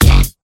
bwomp.wav